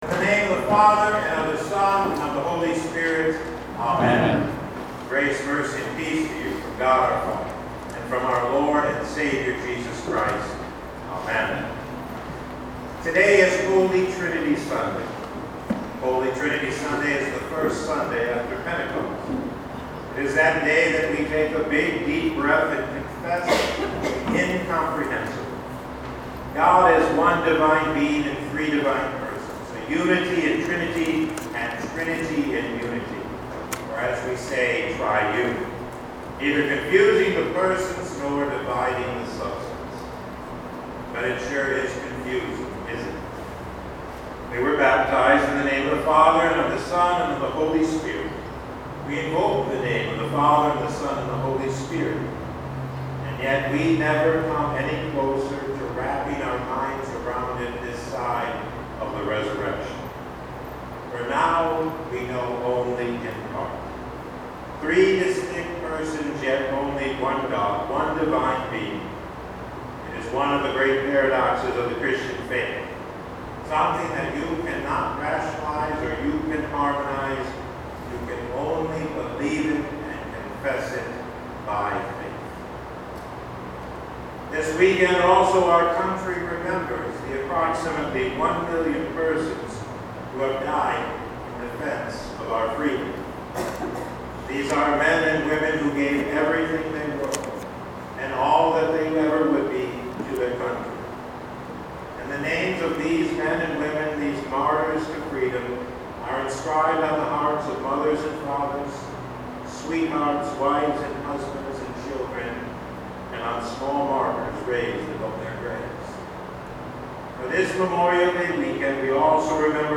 Past Sermons (Audio) - St. Luke Lutheran Church